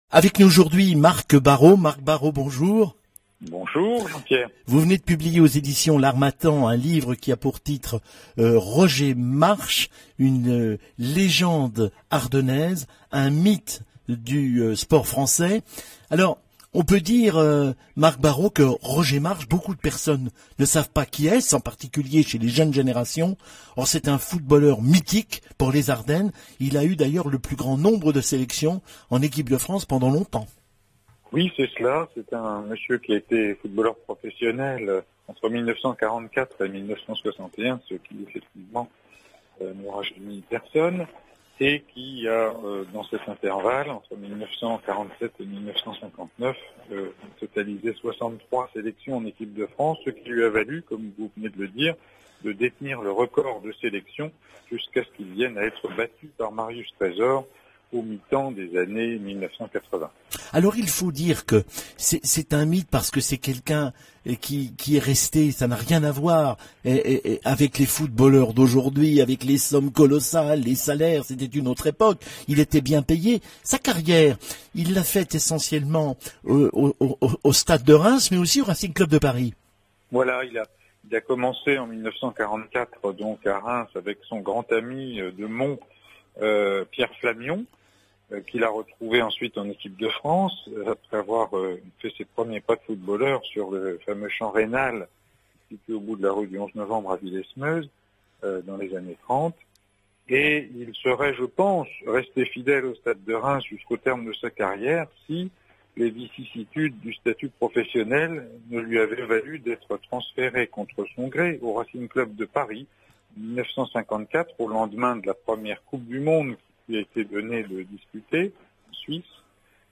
Émission Radio
Interview RCF